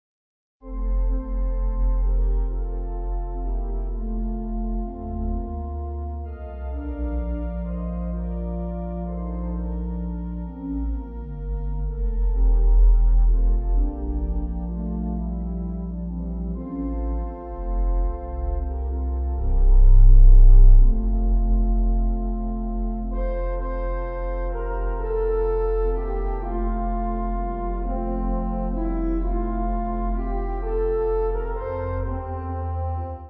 Organ
Easy Listening   F/Bb